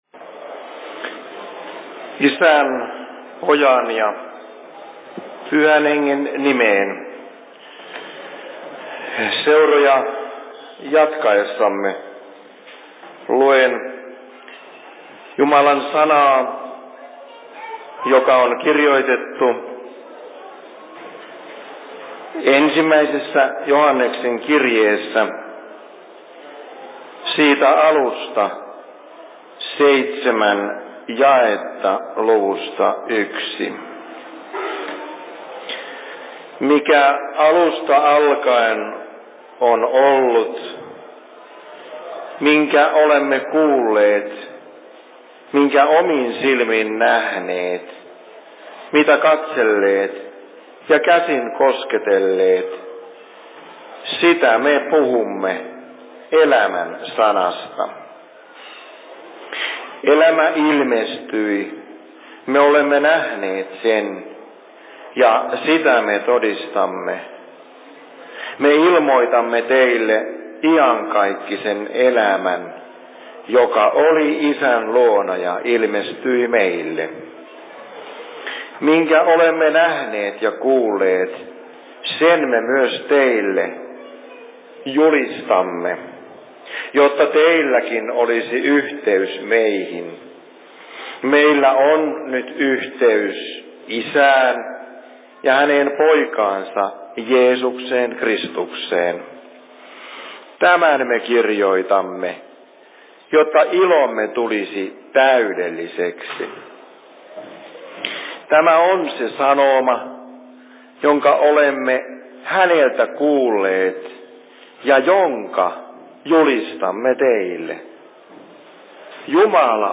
Seurapuhe Lahden RY:llä 04.02.2024 17.20
Paikka: Rauhanyhdistys Lahti